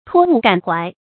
托物感懷 注音： ㄊㄨㄛ ㄨˋ ㄍㄢˇ ㄏㄨㄞˊ 讀音讀法： 意思解釋： 假借事物抒發胸懷。